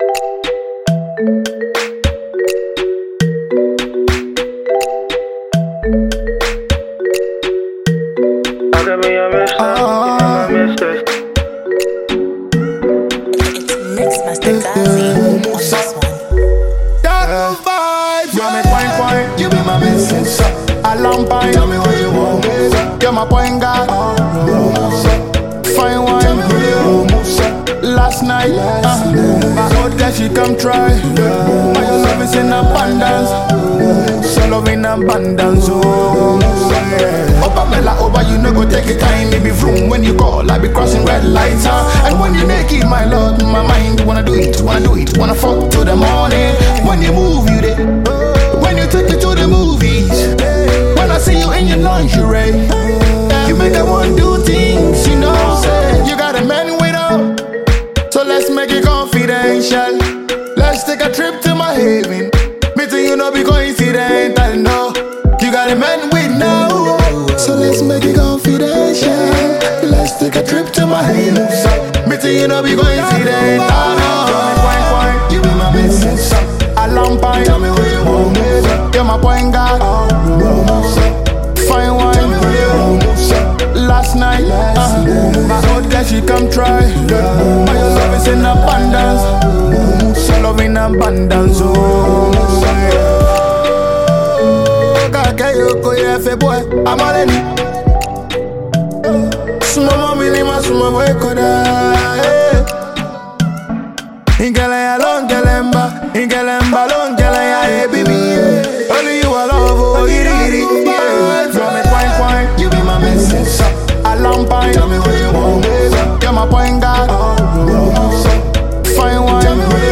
Ghanaian Afropop/Afrobeat singer and songwriter